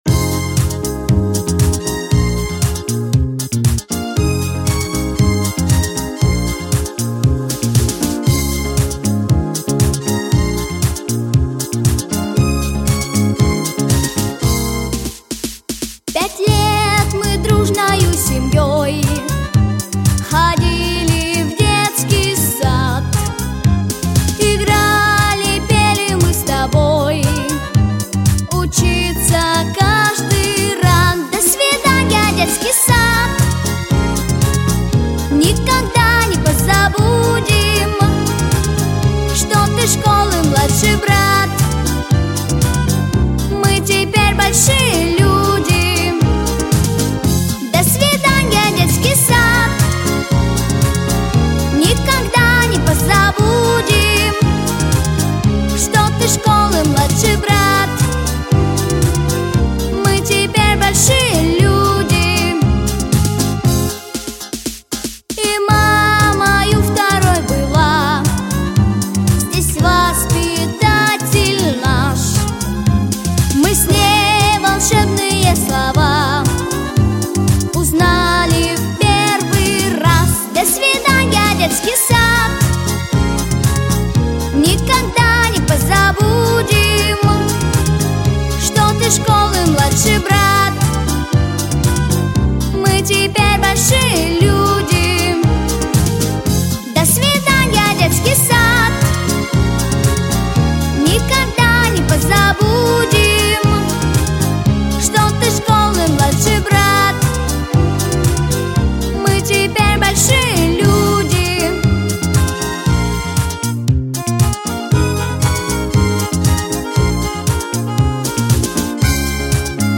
🎶 Детские песни / Детский сад / Выпускной в детском саду